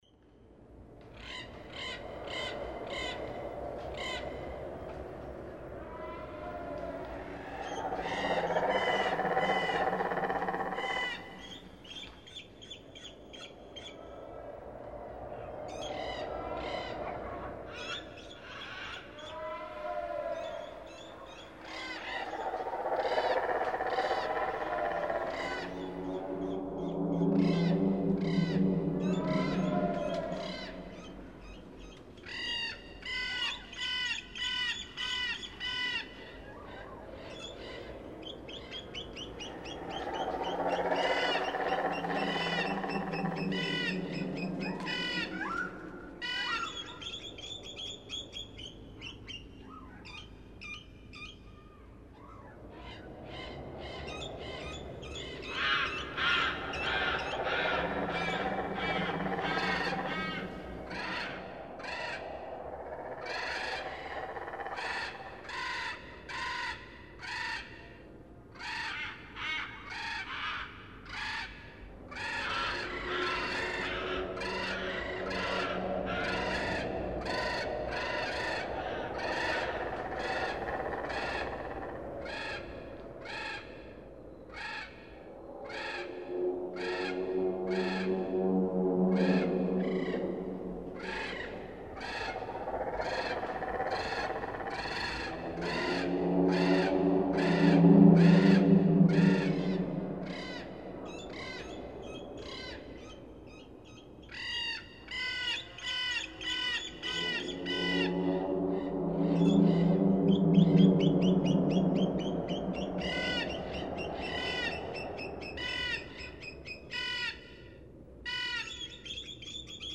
Mystical fairy forest: